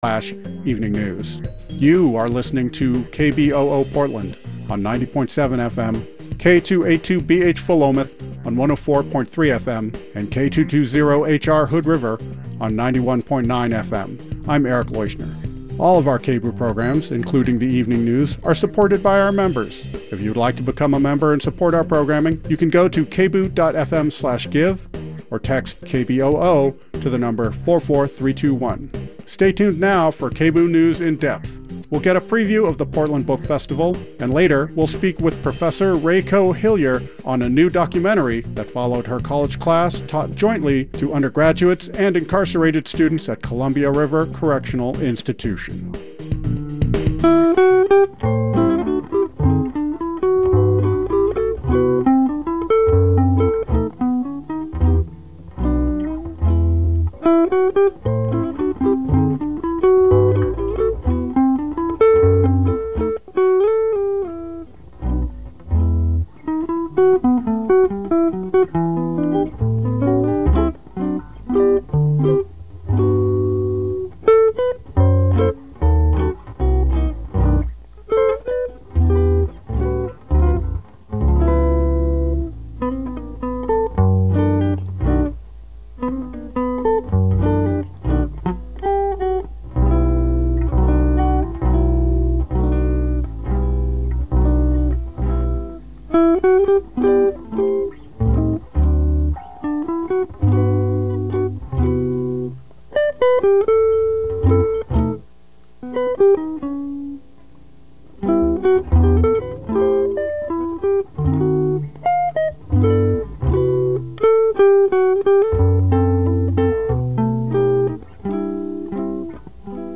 Hosted by: KBOO News Team